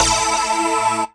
voiTTE64017voicesyn-A.wav